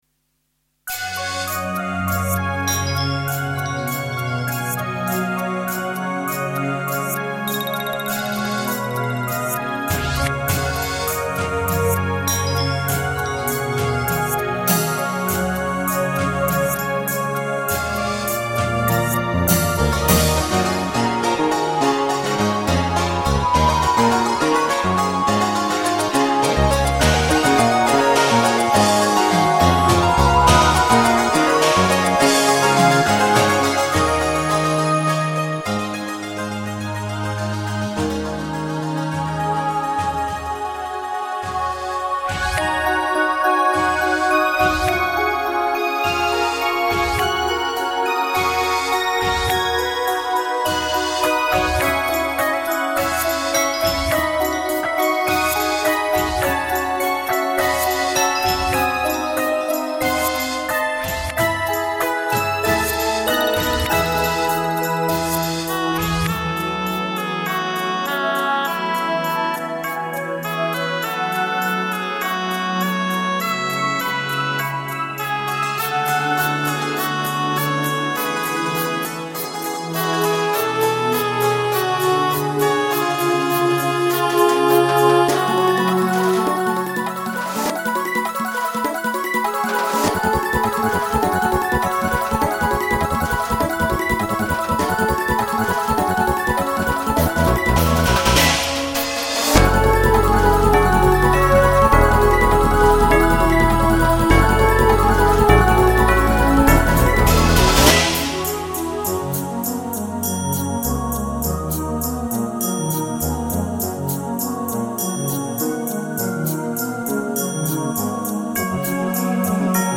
De folkloristische 'dulcimer' speelt hierin de hoofdrol temidden van elektronische koren en instrumenten.